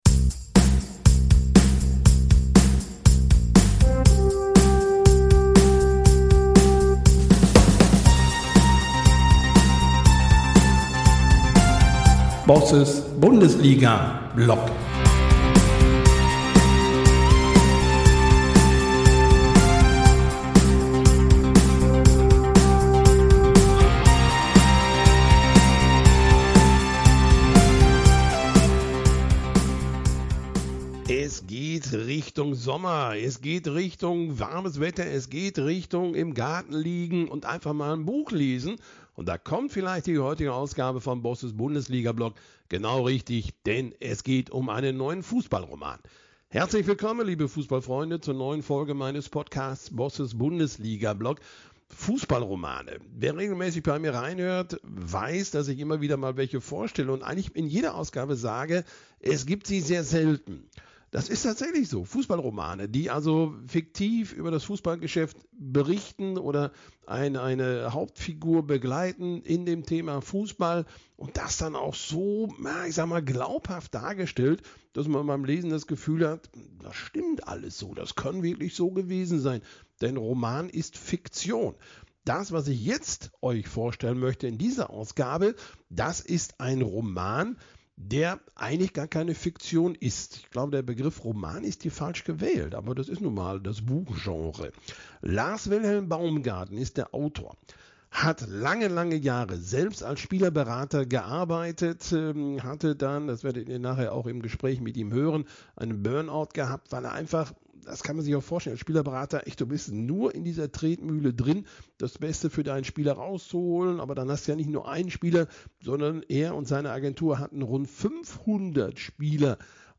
Wir sprechen darüber, was passiert, wenn Leistung zur Identität wird – und was bleibt, wenn Erfolg und Applaus plötzlich wegfallen. Es geht um mentale Stärke, Brüche, Neuanfänge und die Frage, wie junge Talente und ihr Umfeld mit den Herausforderungen des Systems umgehen können. Ein Gespräch über Fußball, das Buch – und über das Leben dahinter.